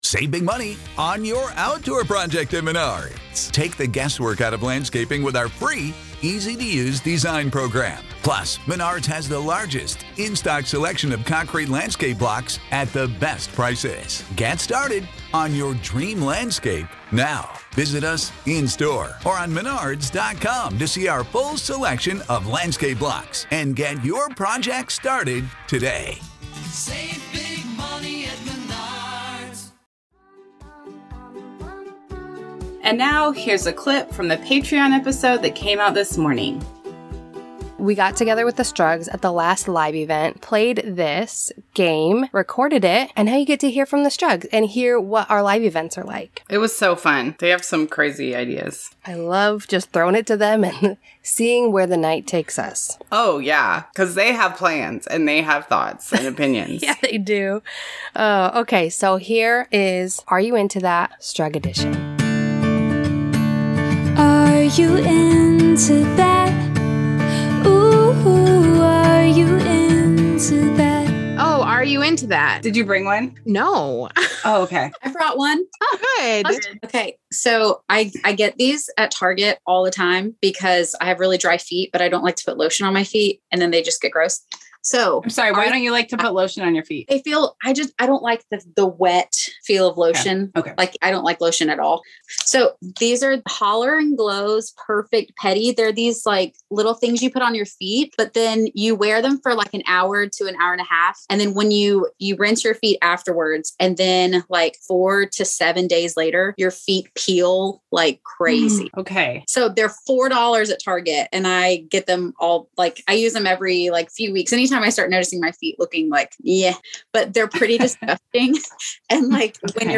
This week we are sharing a segment we recorded from our last live event with the Patreon Struggs.